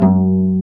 Index of /90_sSampleCDs/Roland LCDP13 String Sections/STR_Vcs Marc&Piz/STR_Vcs Pz.3 dry